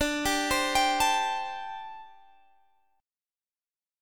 Listen to D7sus4 strummed